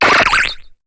Cri de Cheniti dans Pokémon HOME.